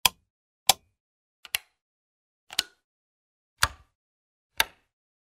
Звуки переключателя, выключателя
Набор звуков для установки настенного выключателя света